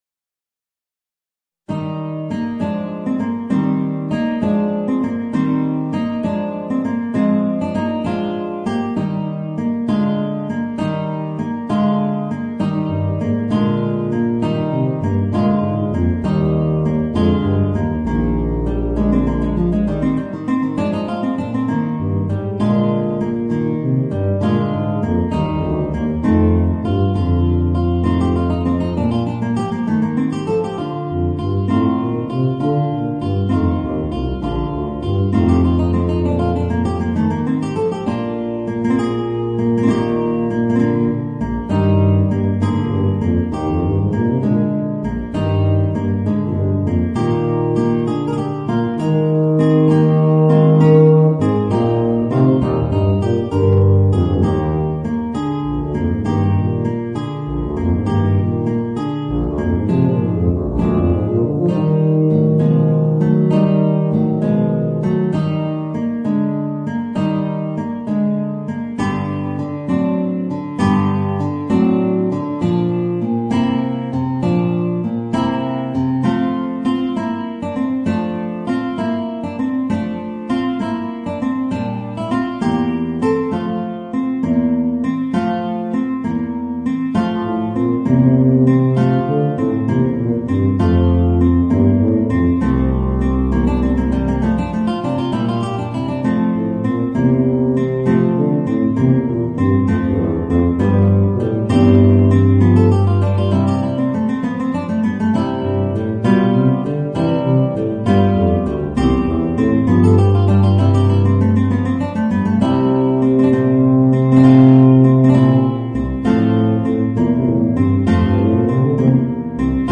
Voicing: Guitar and Bb Bass